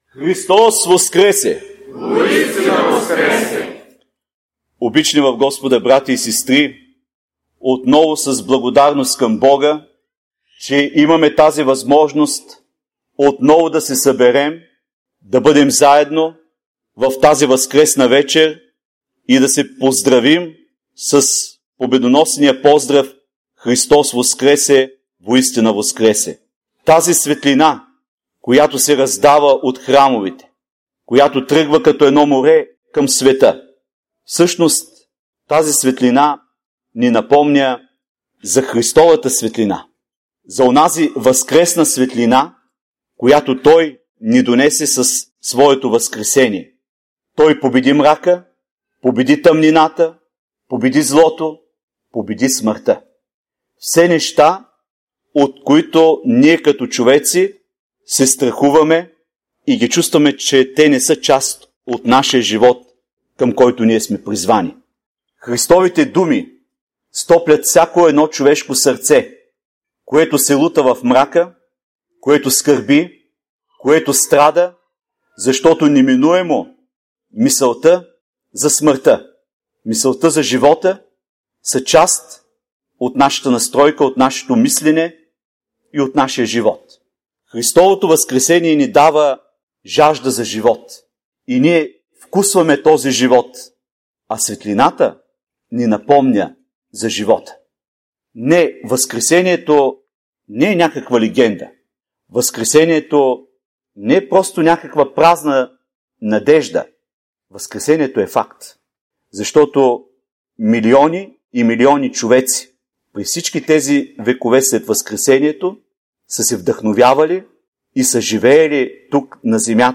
Възкресение Христово - Проповед - Храм "Св.
Чуйте проповедта за Възкресение Христово: